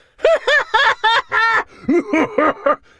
beyond/Assets/Sounds/Enemys/Giant/G2_laugh2.ogg at unity6
G2_laugh2.ogg